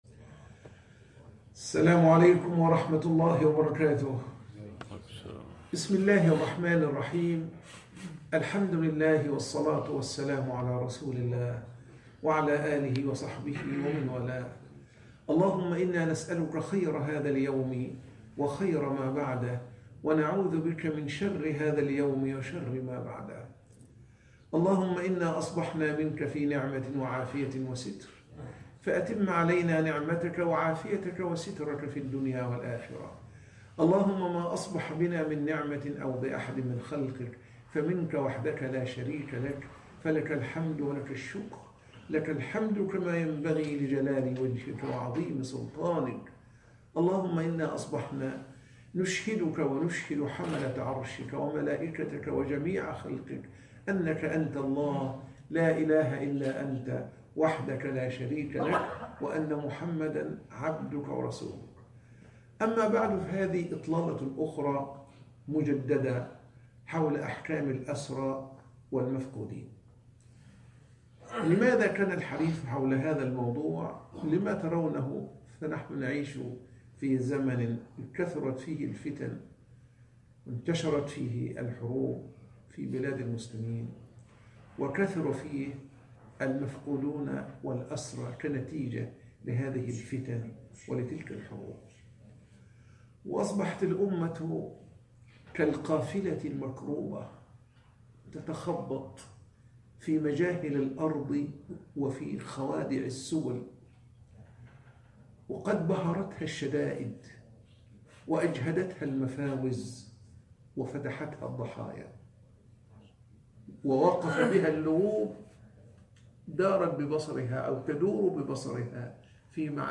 مدة انتظار الأسرى والمفقودين ومصير نسائهم وأموالهم (درس بعد الفجر